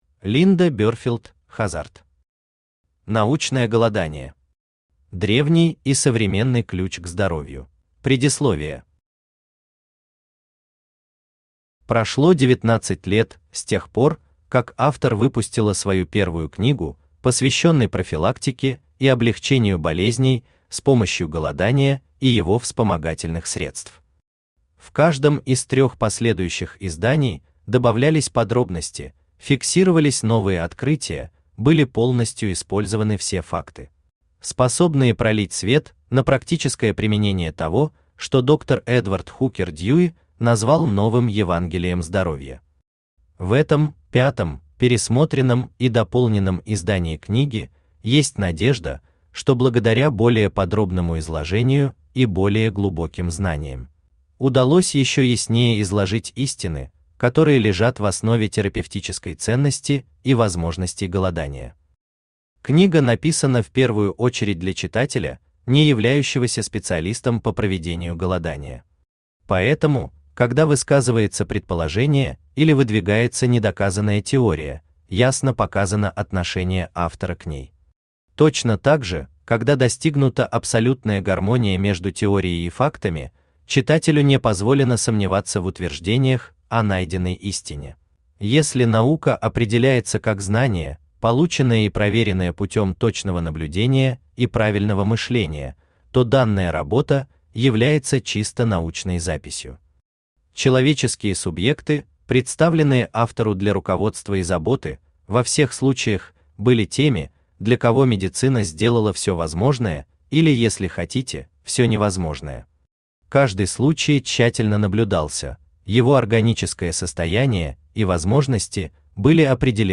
Аудиокнига Научное голодание. Древний и современный ключ к здоровью | Библиотека аудиокниг
Древний и современный ключ к здоровью Автор Линда Берфилд Хаззард Читает аудиокнигу Авточтец ЛитРес.